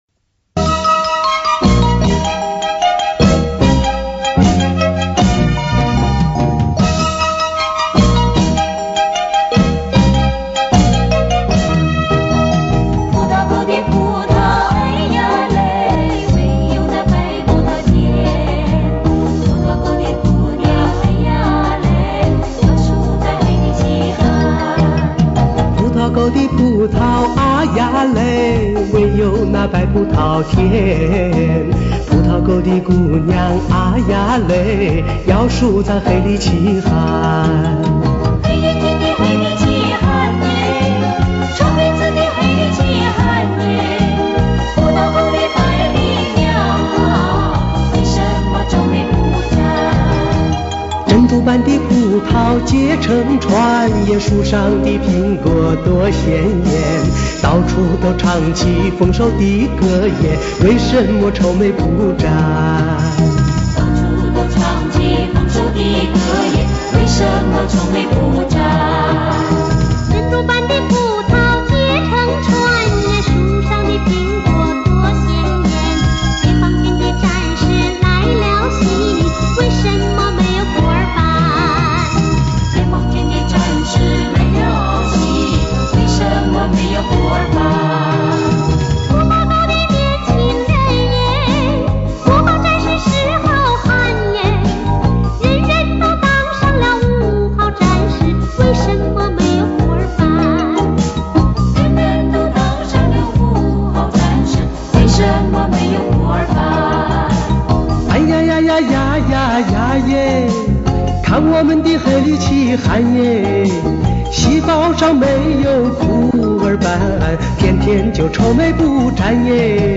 如果哪位朋友知道歌里那个女声是谁，请告诉我一声啊，呵呵。